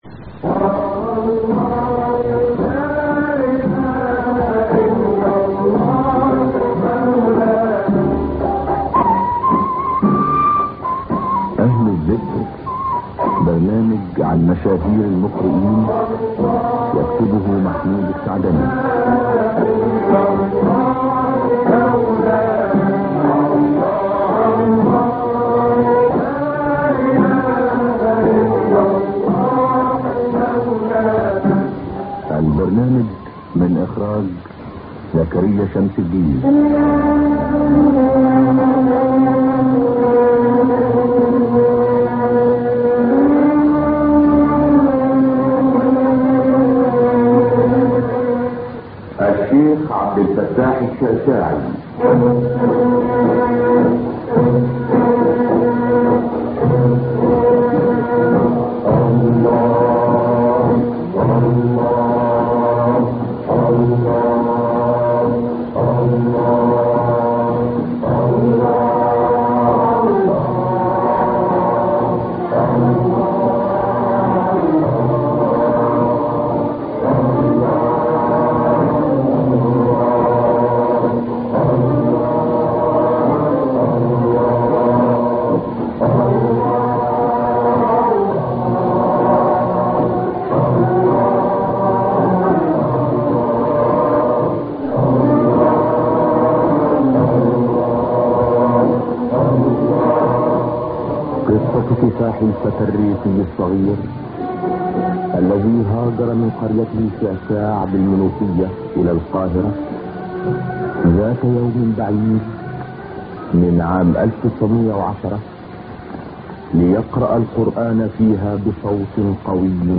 تلاوات القارئ إبراهيم الشعشاعي